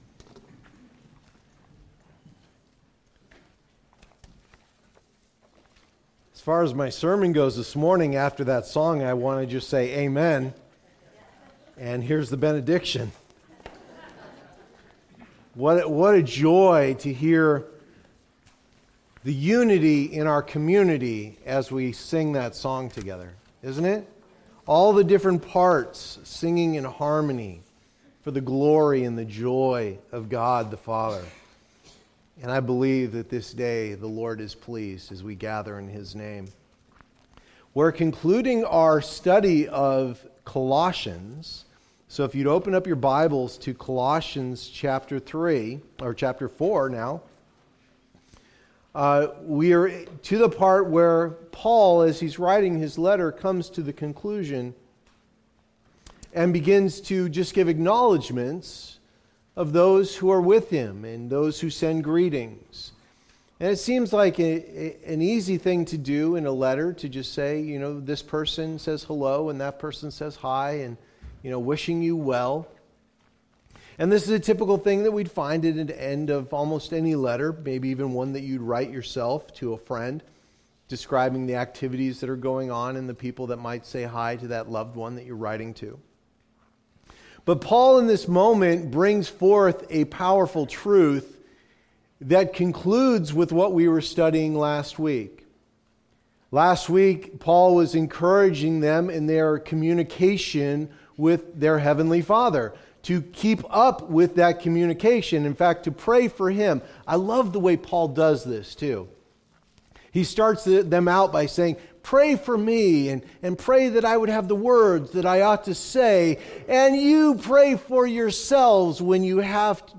Rooted in Christ Passage: Colossians 4:7-18 Services: Sunday Morning Service Download Files Notes Previous Next